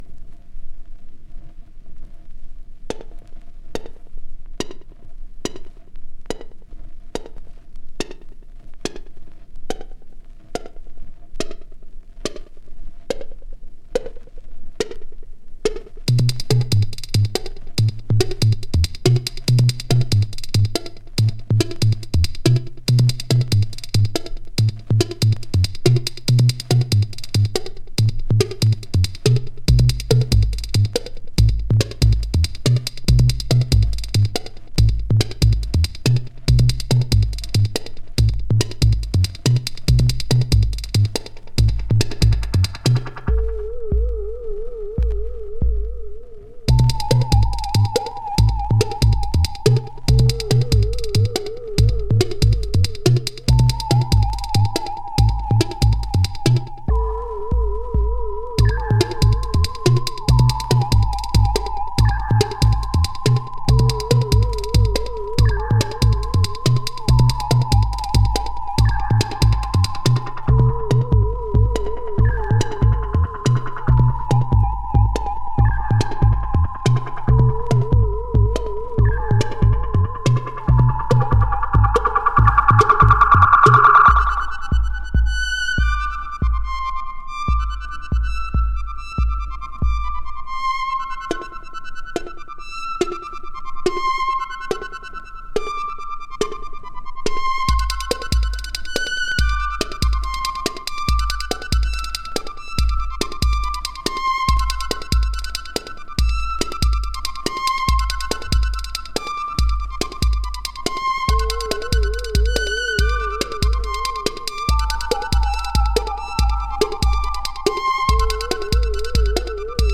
【TECHNO】
カナダ産90’sテクノ！
音数の少ないミニマルなサウンドにハマるカナダ産レア90’sテクノ盤！